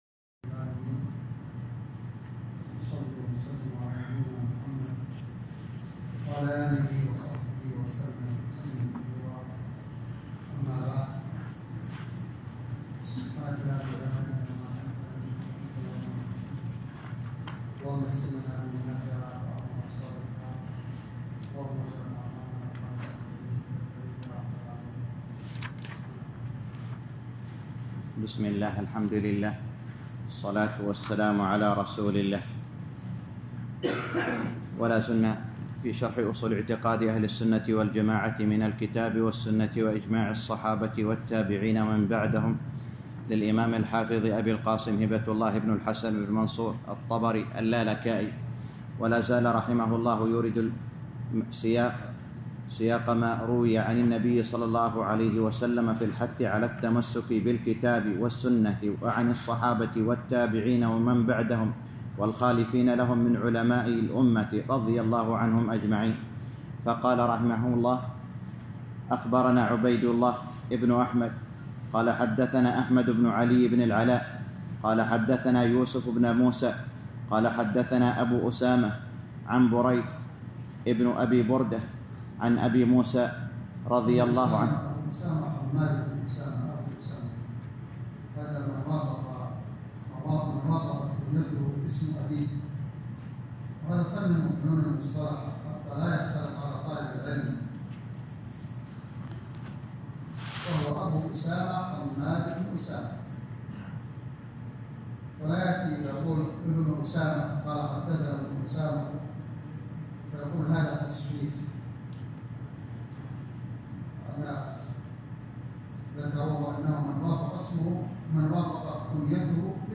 الدرس التاسع عشر - شرح أصول اعتقاد اهل السنة والجماعة الامام الحافظ اللالكائي _ 19